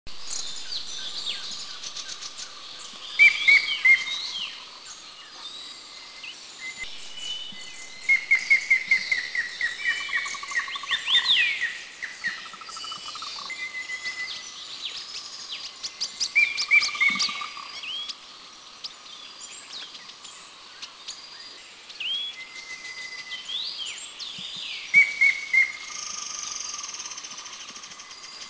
Grey-headed Woodpecker Picus canus  tancolo
B2A_Grey-headedWoodpeckerAnmashan410_SDW.mp3